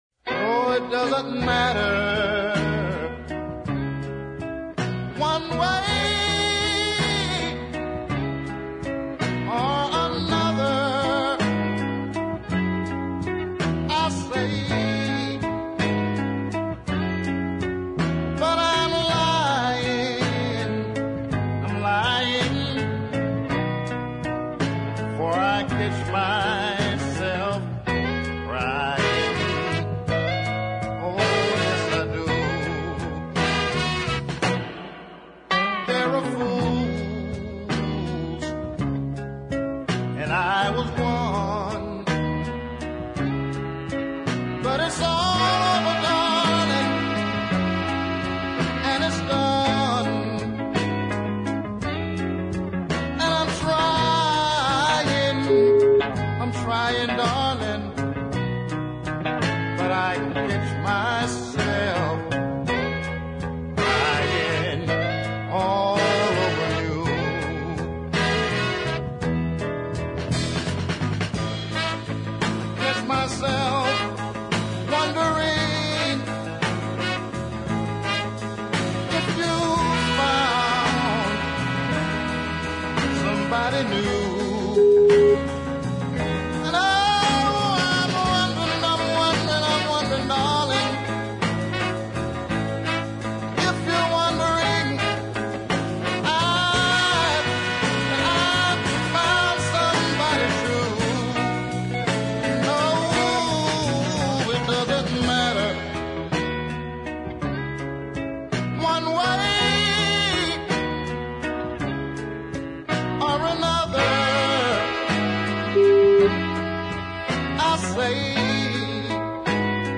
but I do recognise a fine Memphis soul 45 when I hear one.
A good deep soul side.